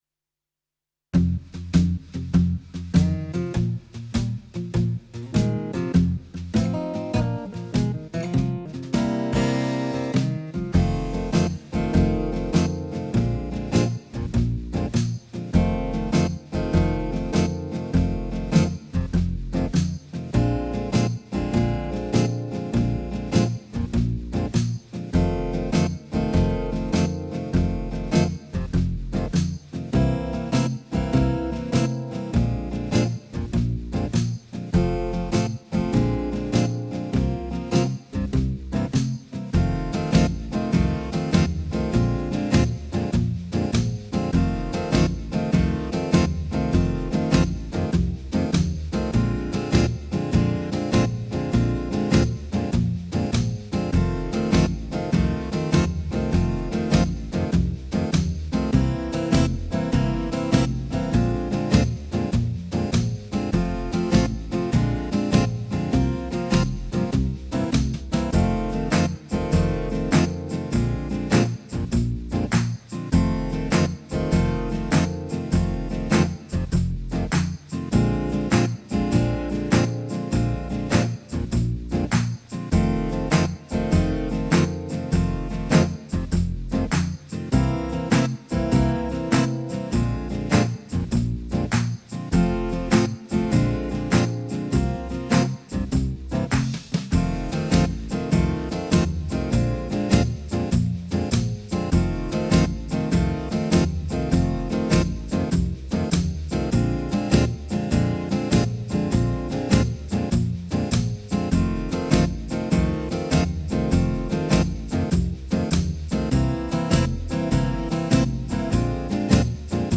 Home > Music > Blues > Bright > Smooth > Medium